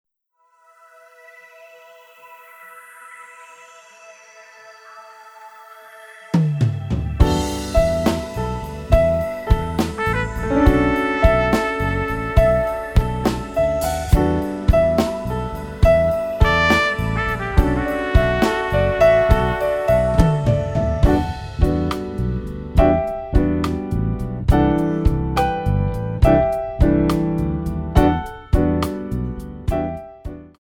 Modern / Lyrical Jazz
8 bar intro
ballade